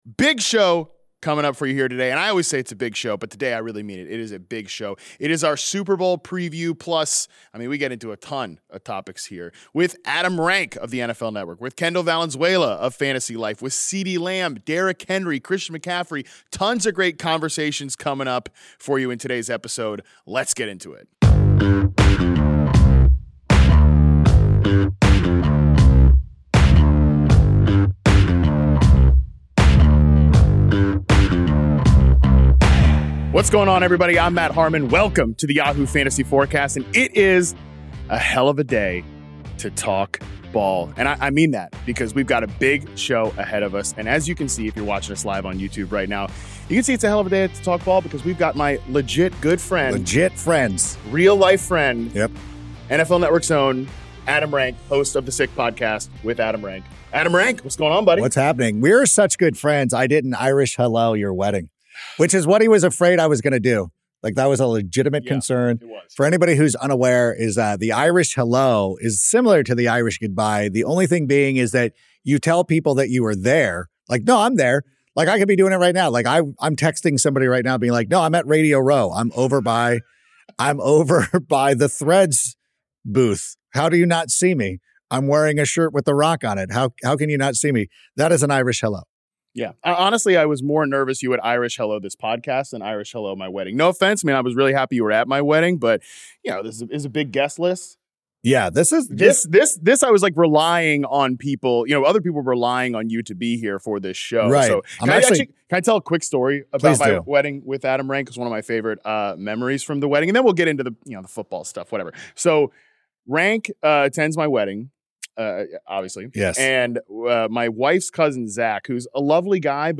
LIVE from San Francisco